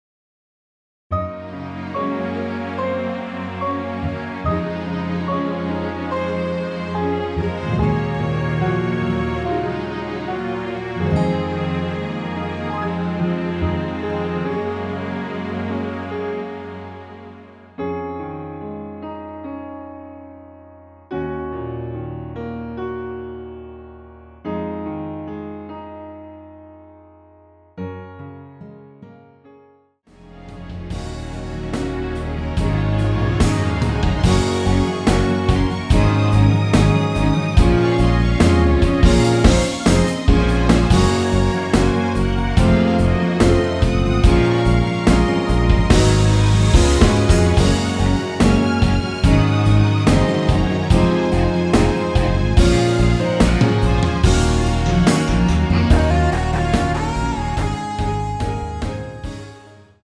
원키에서(-1)내린 MR 입니다.
Db
앞부분30초, 뒷부분30초씩 편집해서 올려 드리고 있습니다.
곡명 옆 (-1)은 반음 내림, (+1)은 반음 올림 입니다.